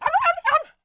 client / bin / pack / Sound / sound / monster / stray_dog / dead_1.wav